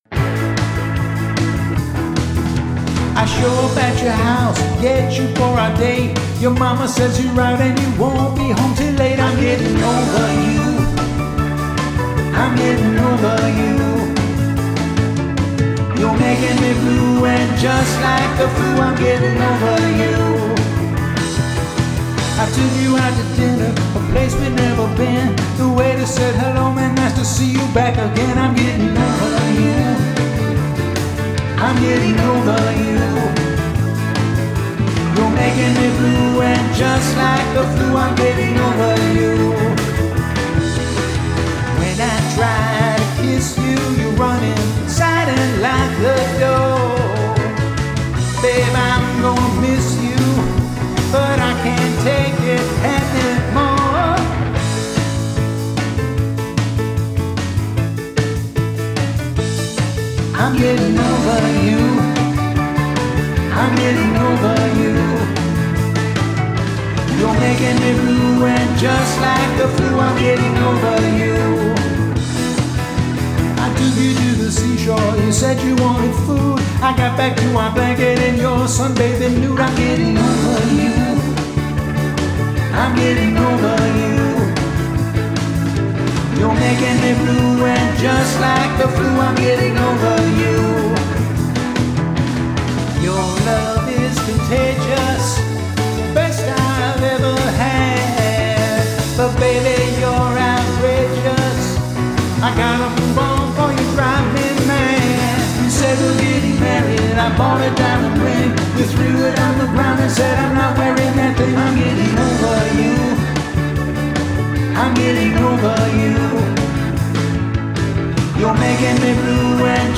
Genre: rock, pop.